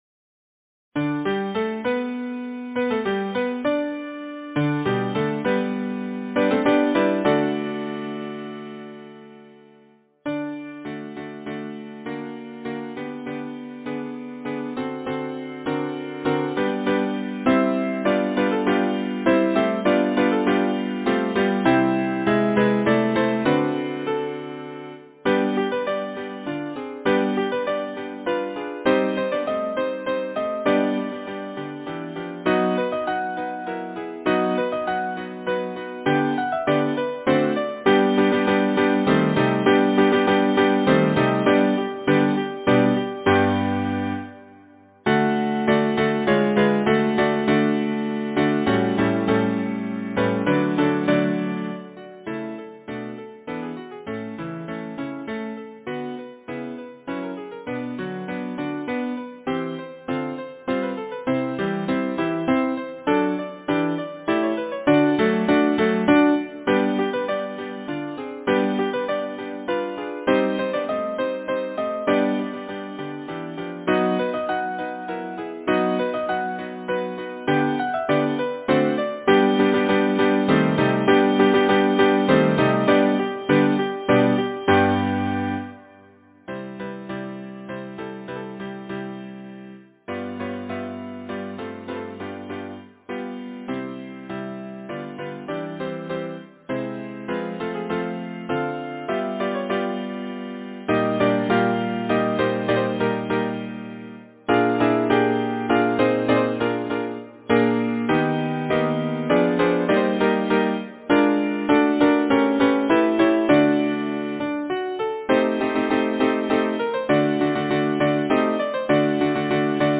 Title: Gitanella Composer: Charles Gounod Lyricist: Florence Emily Ashley Number of voices: 4vv Voicing: SATB Genre: Secular, Partsong
Language: English Instruments: A cappella